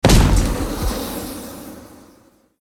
academic_skill_skyline_01_fire.ogg